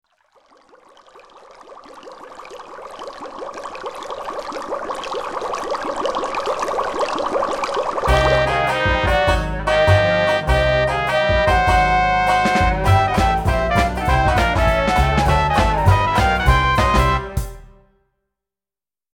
Roland Fantom XR Version